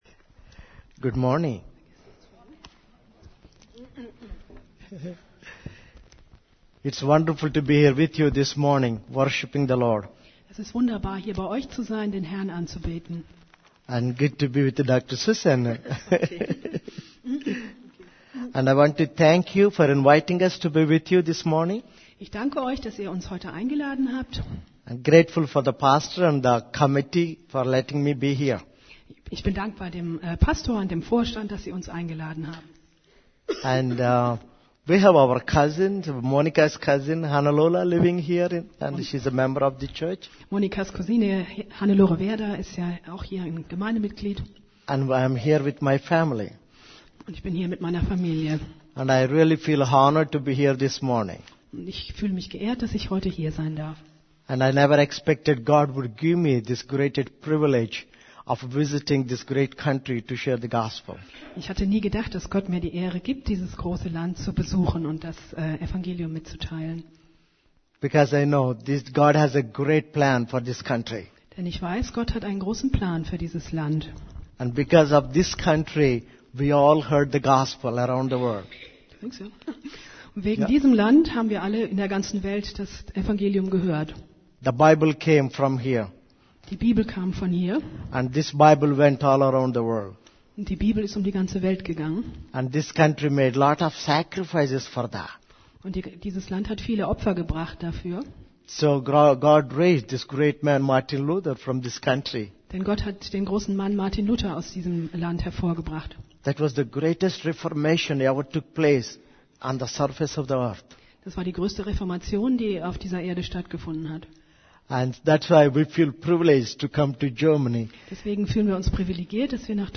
> Übersicht Predigten Das Geheimnis der Segnungen Gottes Predigt vom 06.